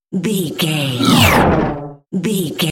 Whoosh electronic shot
Sound Effects
futuristic
high tech
whoosh